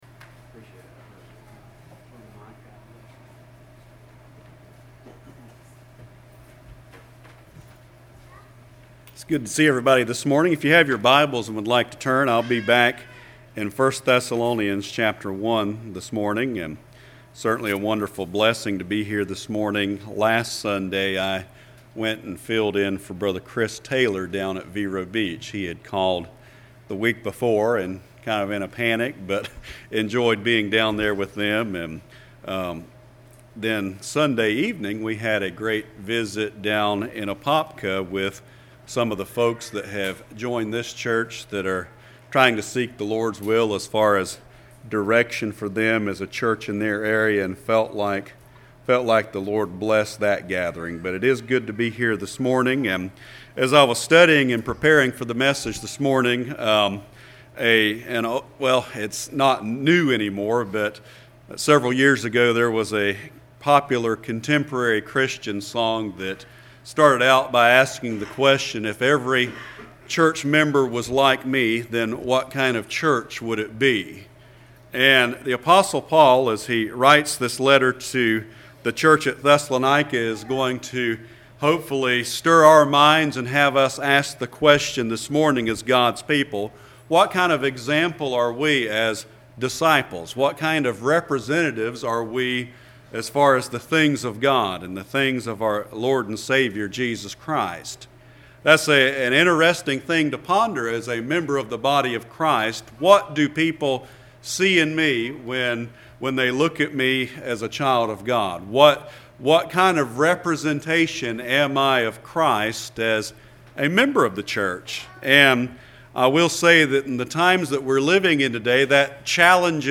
07/01/18 Sunday Morning
Service Type: Sunday Morning